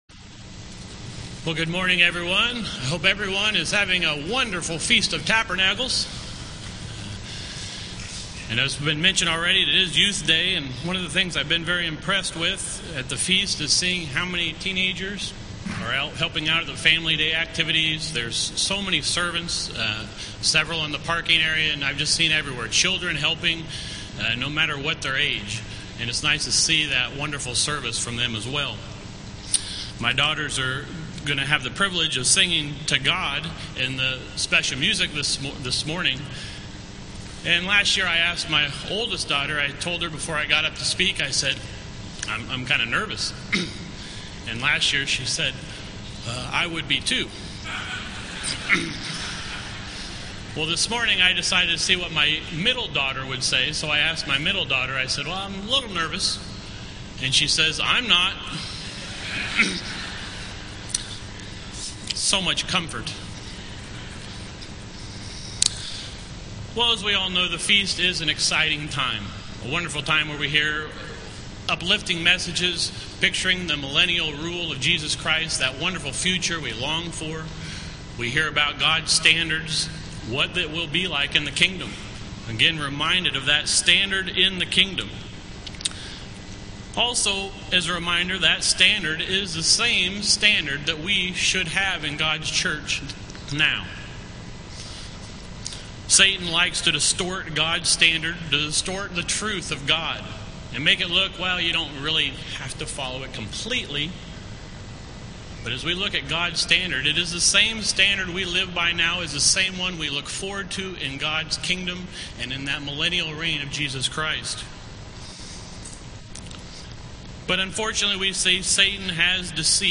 This sermon was given at the Wisconsin Dells, Wisconsin 2009 Feast site.